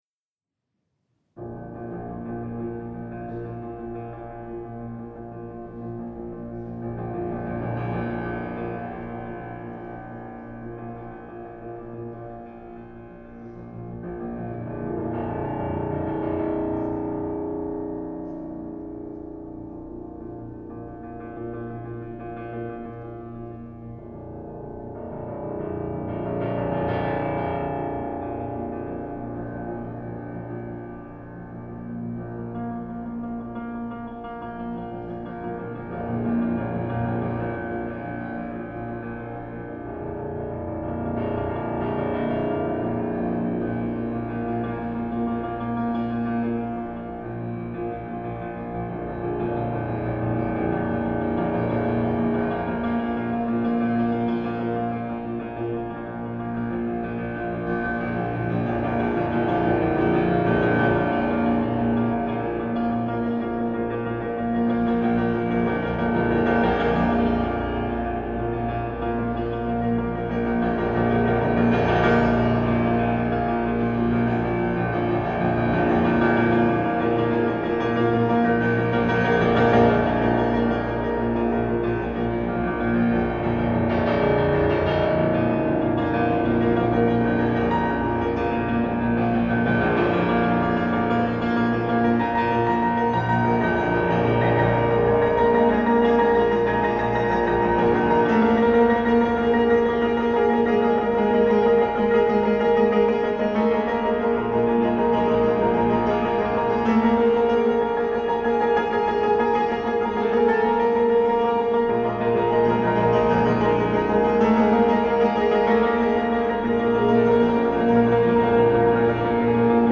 experimental composer